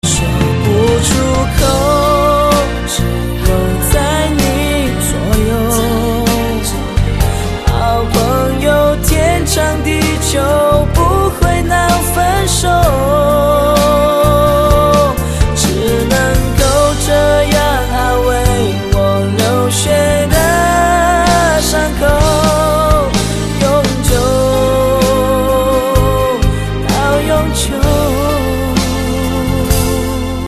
M4R铃声, MP3铃声, 华语歌曲 23 首发日期：2018-05-15 02:52 星期二